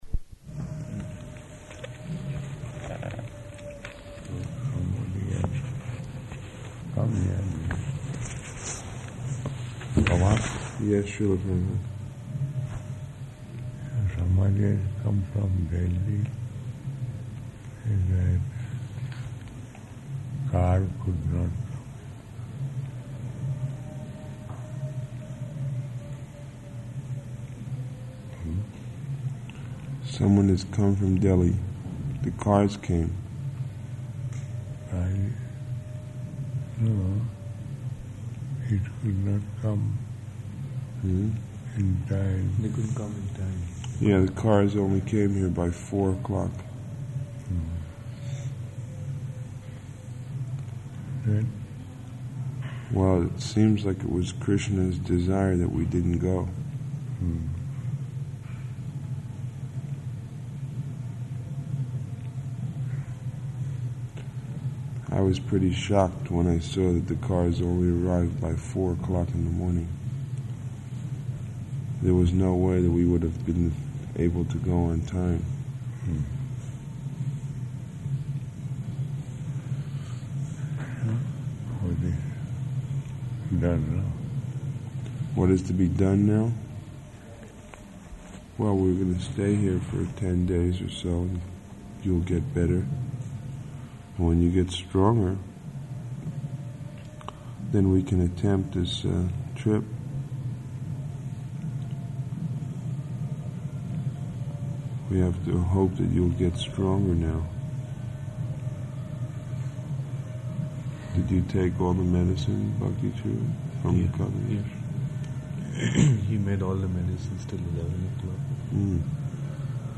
Prabhupāda Vigil --:-- --:-- Type: Conversation Dated: November 3rd 1977 Location: Vṛndāvana Audio file: 771103R1.VRN.mp3 Prabhupāda: So somebody else come near me.